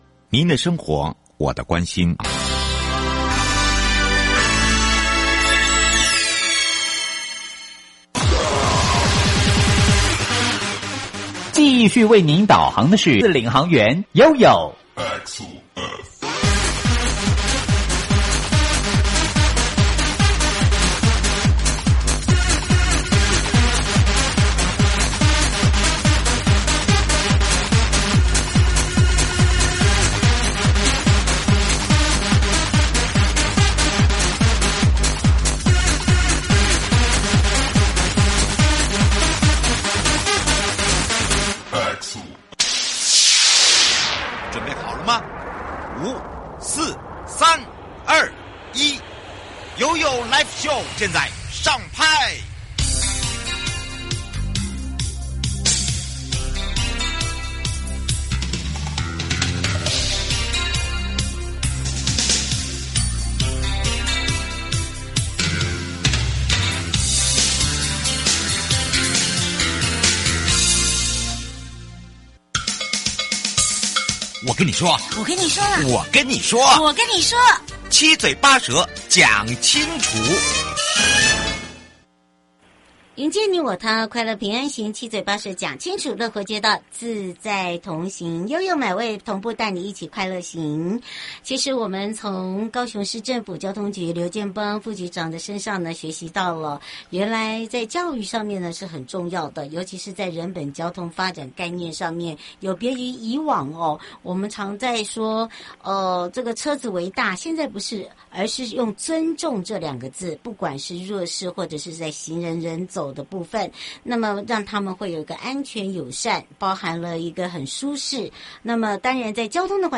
節目內容： 高雄市政府交通局–劉建邦副局長(下集)